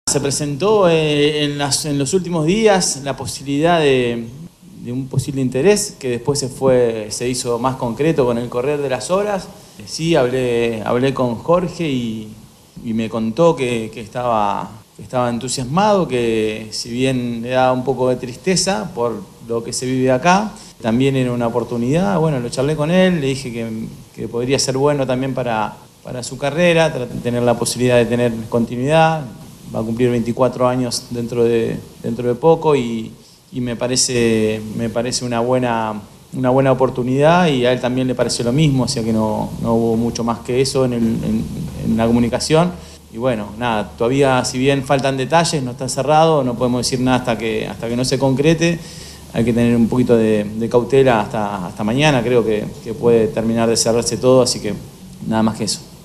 (Marcelo Gallardo, DT de River Plate)
“Se presentó la posibilidad de un interés que luego se hizo más concreto. Hablé con Jorge y me contó que estaba entusiasmado. Que le daba tristeza por lo que vivió acá, pero lo charlé con él y pensamos que podría ser bueno para su carrera”, sostuvo Gallardo en rueda prensa.